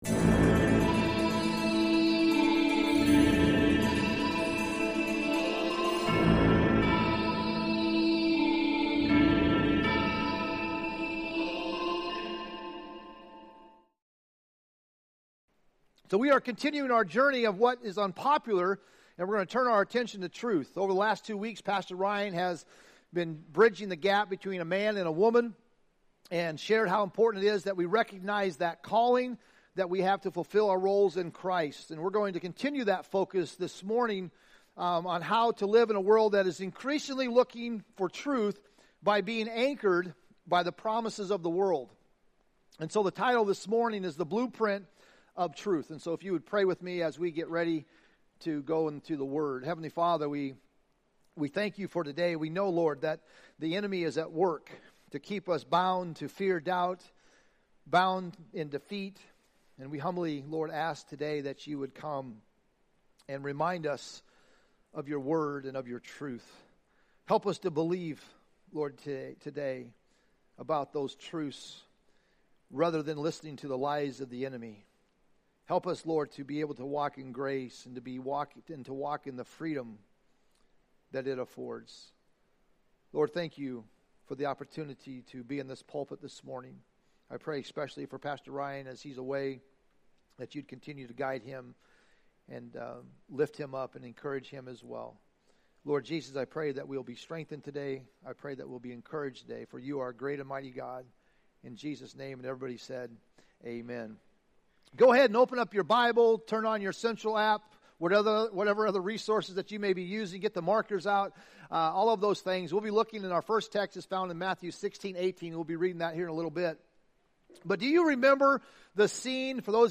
We're in week 3 of our sermon series - Unpopular - with the message, "The Blueprint of Truth" - inspired by Matthew 16:18.